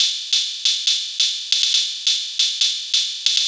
ELECTIC HH-R.wav